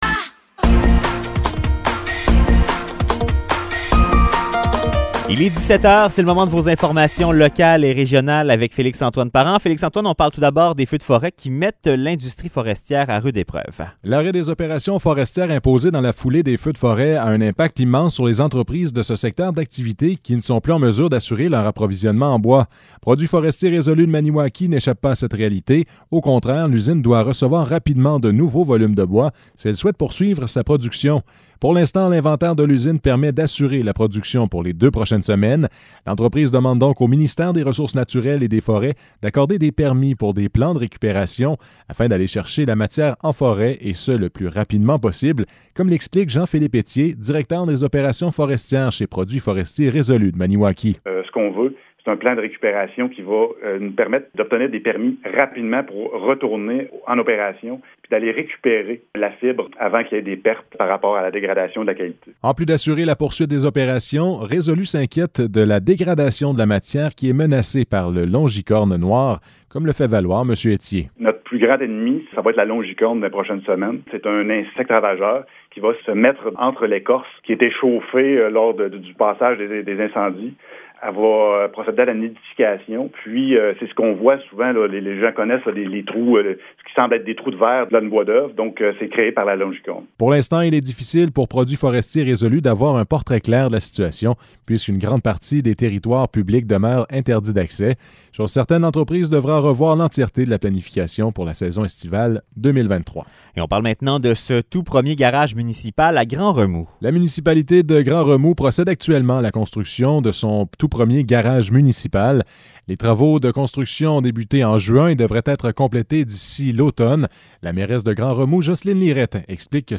Nouvelles locales - 12 juin 2023 - 17 h